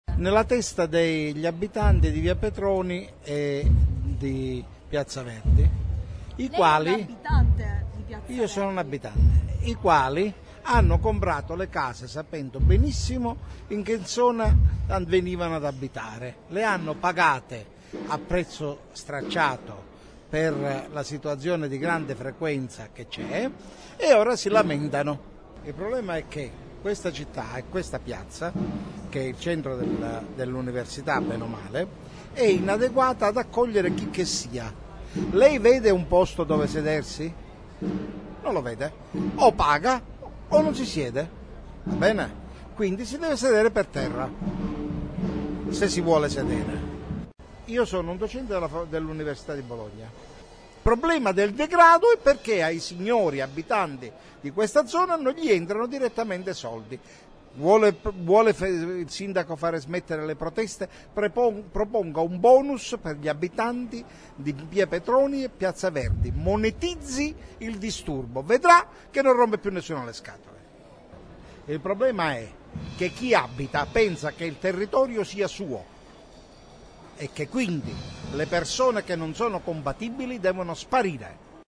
Da un giro in piazza, registratore alla mano, e due chiacchiere con chi la piazza la vive ma anche con chi in piazza ci vive, sono emersi i diversi punti di vista che alimentano il dibattito.
residente